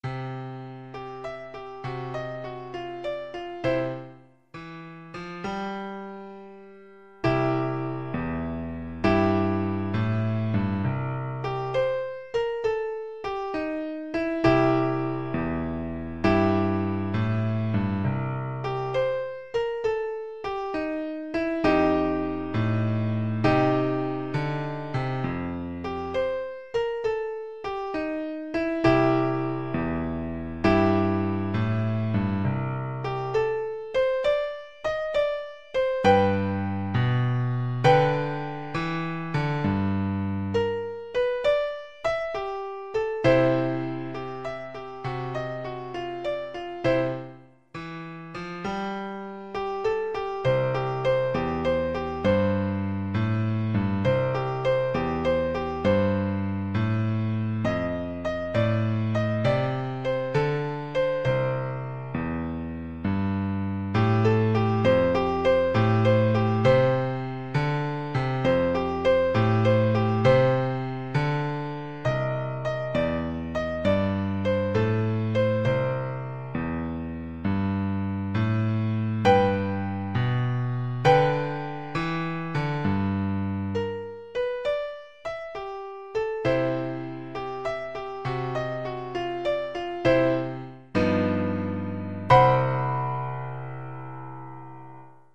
No parts available for this pieces as it is for solo piano.
Piano pieces in C major
Pieces in 6-8 Time Signature